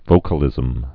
(vōkə-lĭzəm)